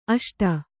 ♪ aṣta